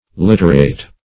Search Result for " liturate" : The Collaborative International Dictionary of English v.0.48: Liturate \Lit"u*rate\ (l[i^]t"u*r[asl]t), a. [L. lituratus, p. p. of liturare to erase, fr. litura a blur.] 1.
liturate.mp3